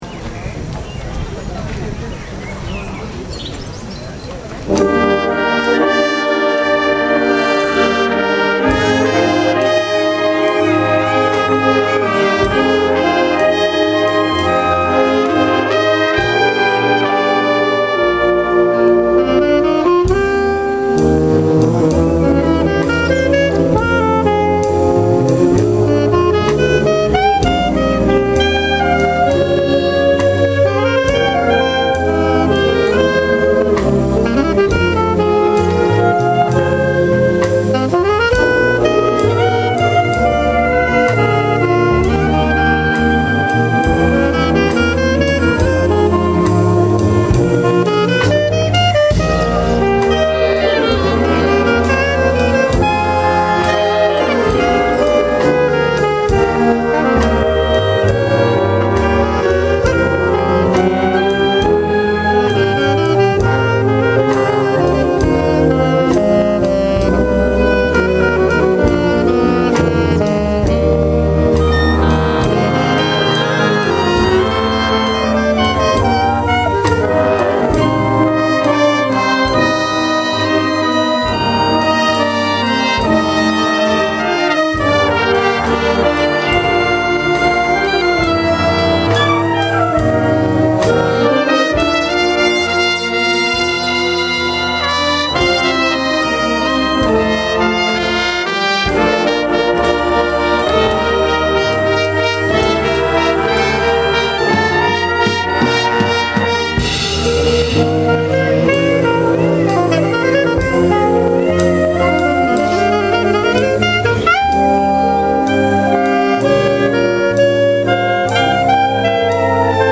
Ascolta una breve registrazione live effettuata durante il concerto del 1 Maggio 2011 in occasione di Flower Music Festival: si tratta di
Reverie, brano per sax contralto solista
e banda.